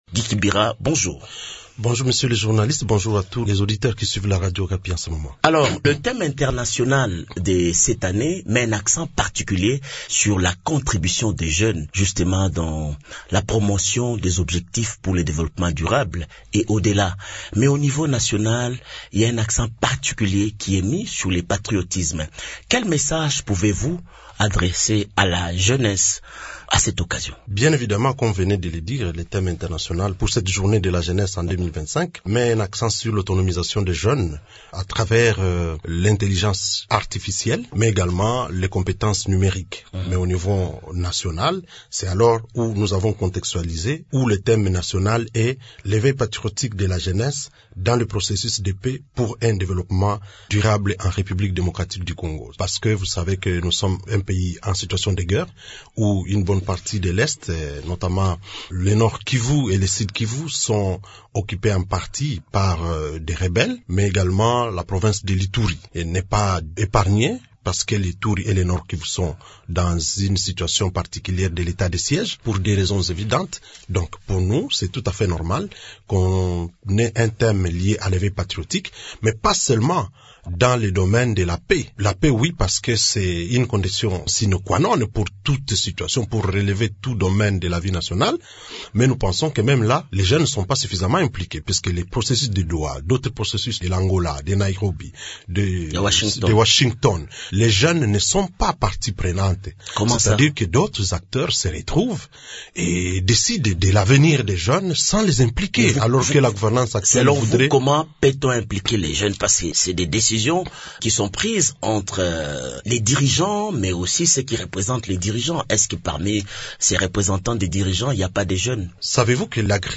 Invité de Radio Okapi ce mercredi 13 aout, M. Kibira Ndoole a souligné que les jeunes ne doivent pas être considérés uniquement comme bénéficiaires des politiques publiques, mais comme acteurs essentiels de la stabilité et du développement du pays.